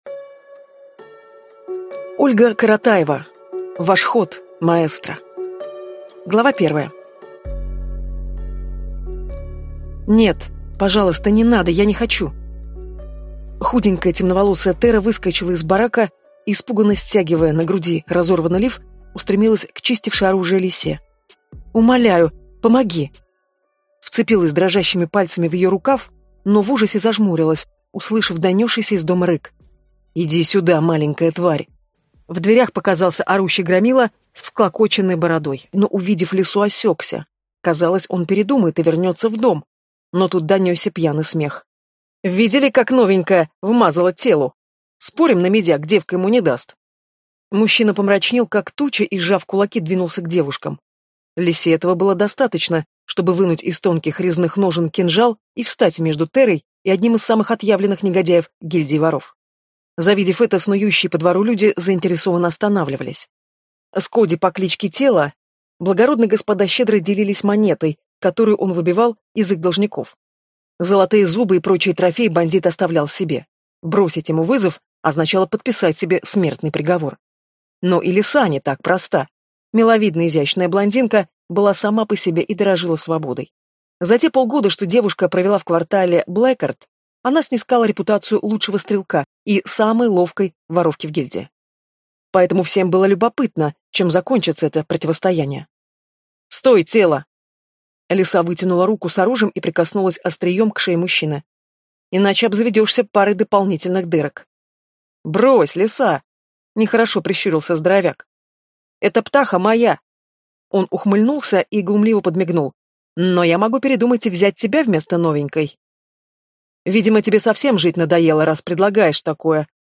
Аудиокнига Ваш ход, Маэстро!
Прослушать и бесплатно скачать фрагмент аудиокниги